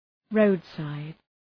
Shkrimi fonetik {‘rəʋdsaıd}